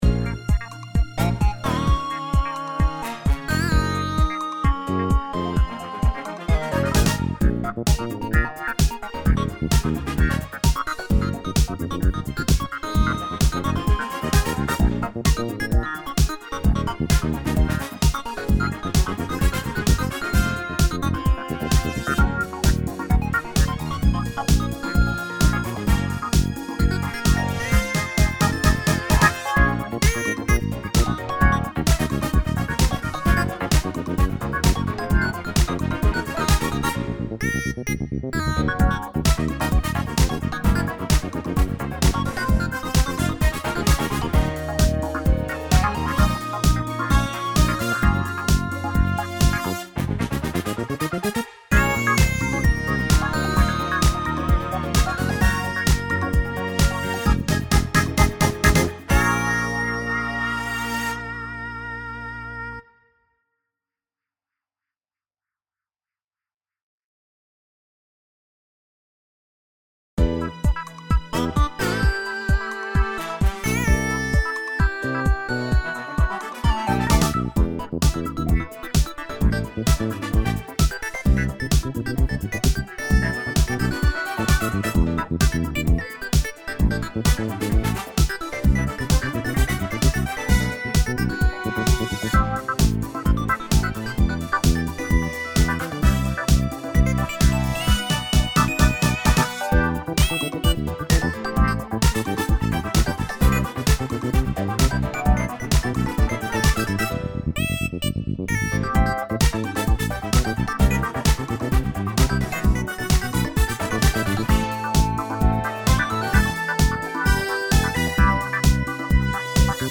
DISCO / FUNK